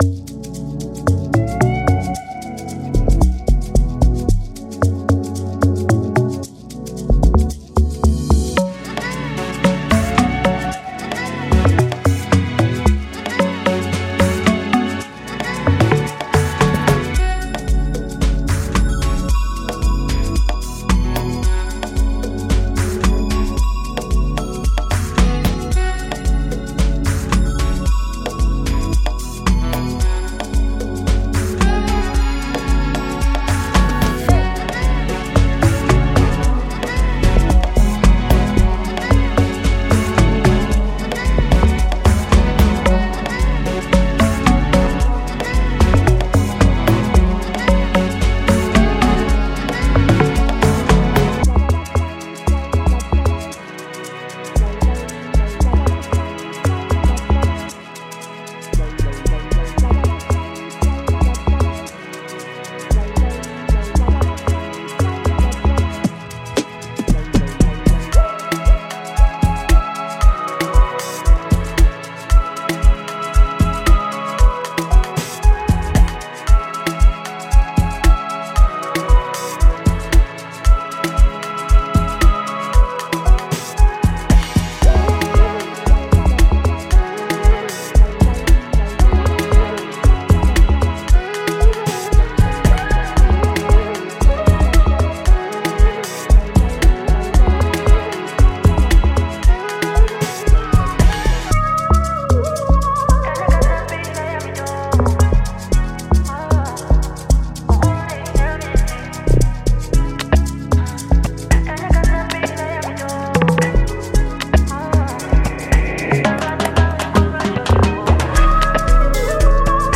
•Authentic blend of Afro and deep house sounds
•Vintage and analog instruments for a unique sonic character
•Dark, moody textures are perfect for creating an atmosphere
•Euphoric elements to uplift your listeners
Demo